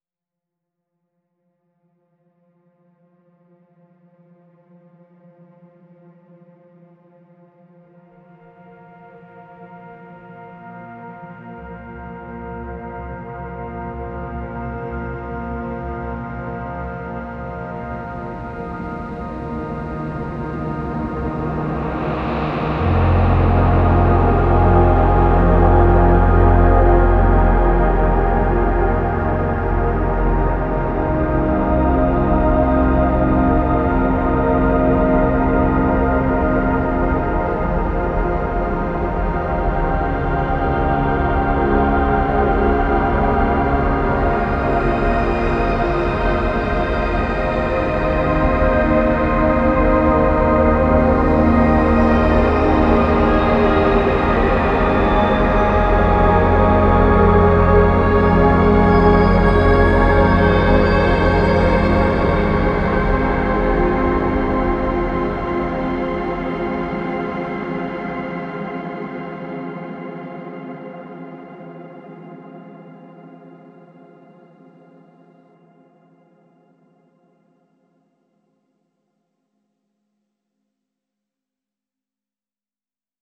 • Жанр: Электро